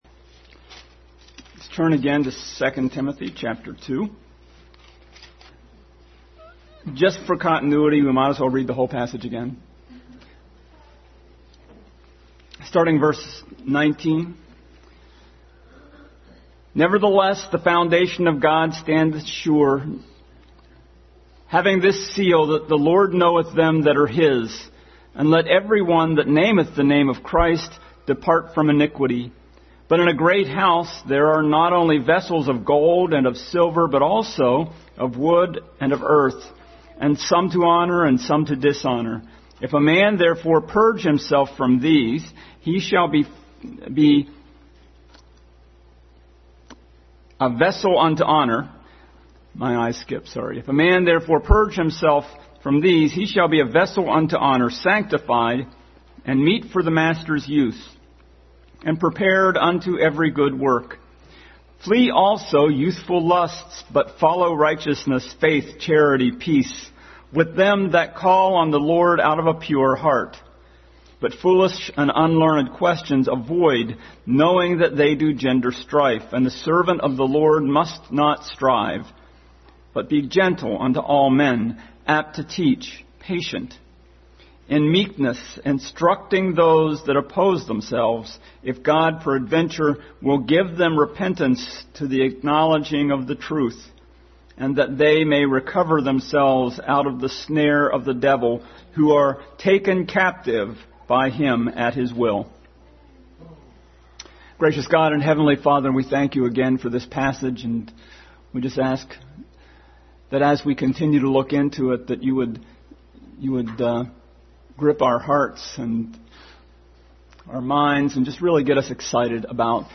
Continued Passage: 2 Timothy 2:19-26, 1 Corinthians 3:8-15 Service Type: Family Bible Hour